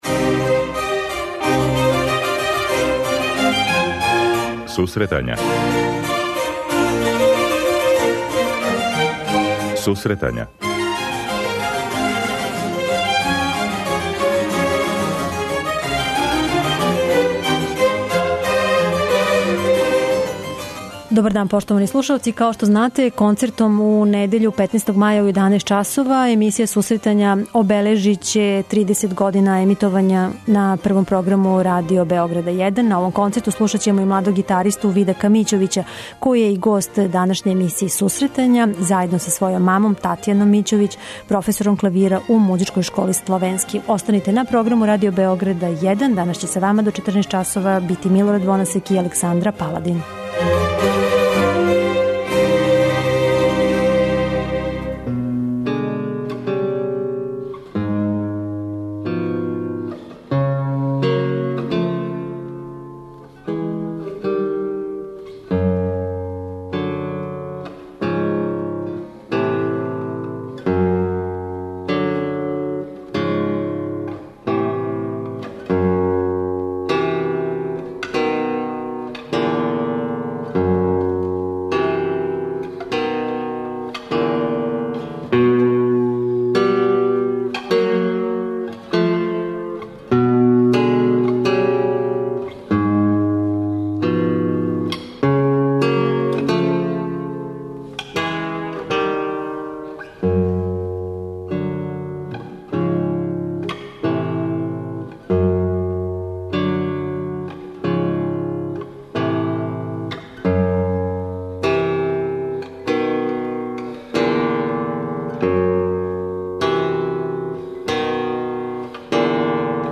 преузми : 23.77 MB Сусретања Autor: Музичка редакција Емисија за оне који воле уметничку музику.